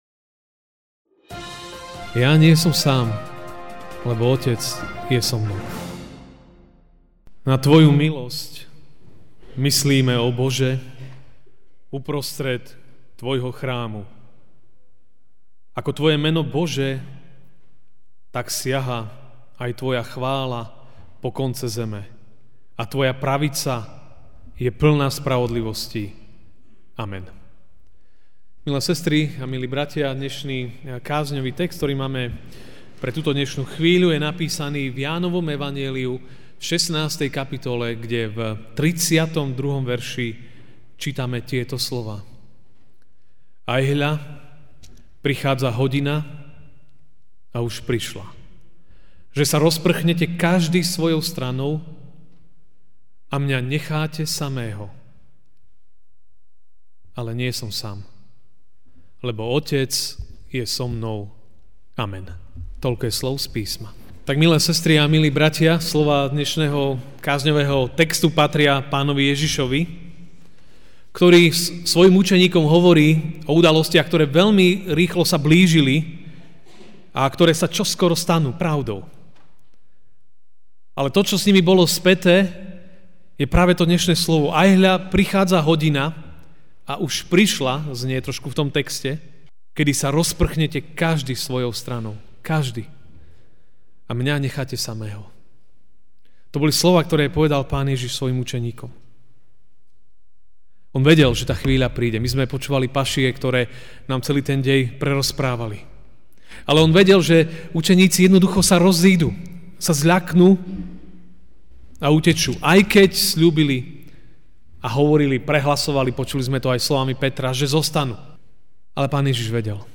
Ranná kázeň: Otec je s nami, aj keď sme sami.